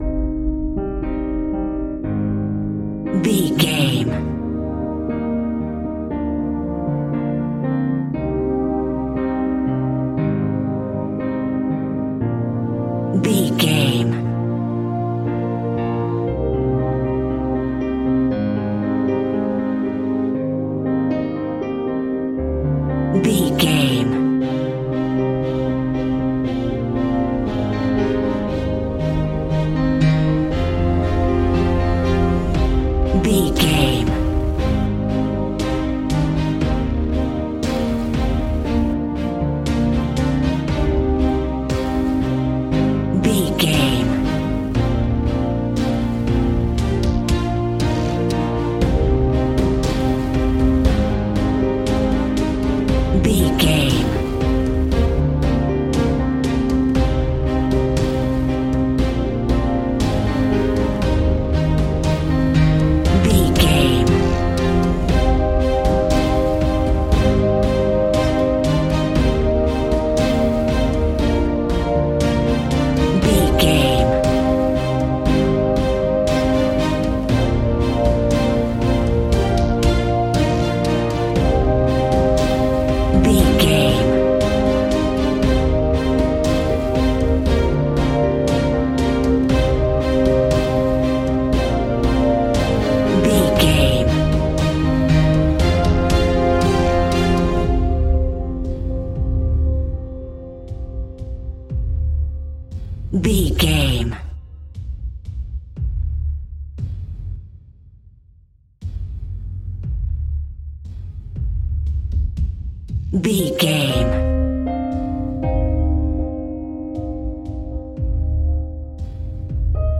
Aeolian/Minor
strings
percussion
synthesiser
brass
violin
cello
double bass